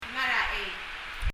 発音
chei　　[? ei]　　　　 魚釣り　　fishing